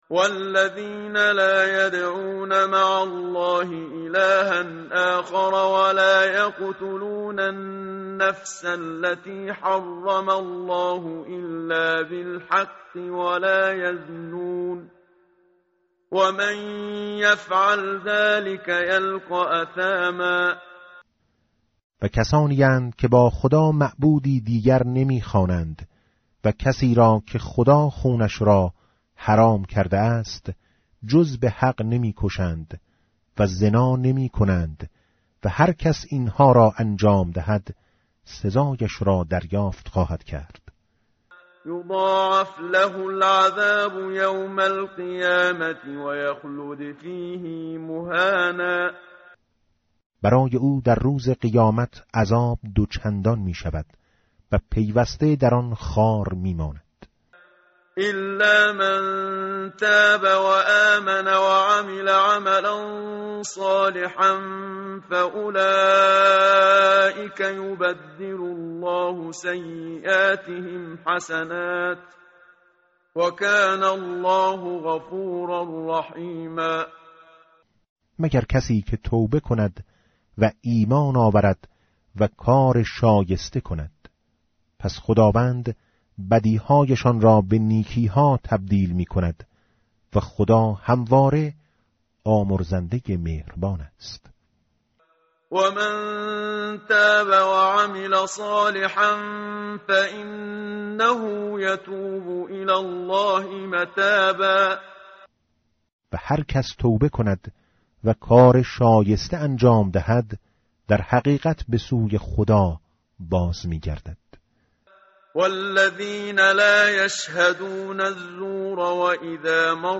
tartil_menshavi va tarjome_Page_366.mp3